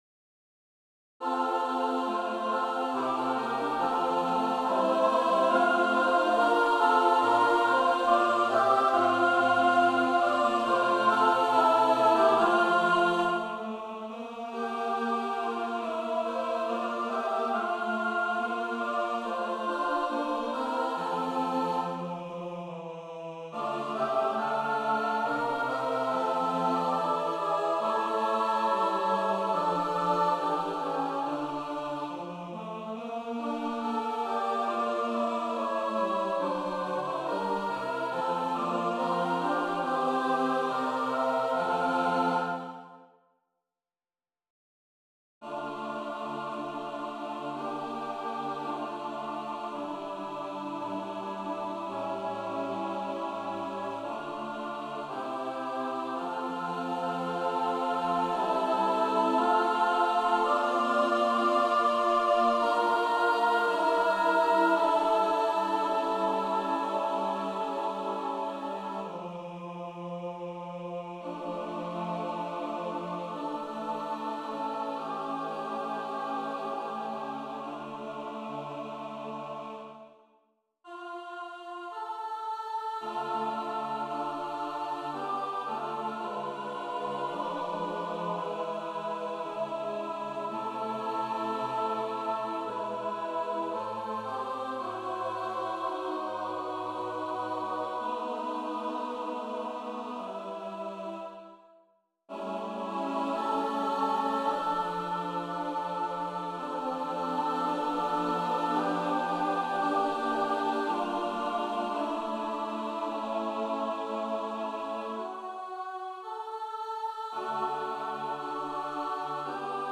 Мини концерт для смешанного хора, a cappella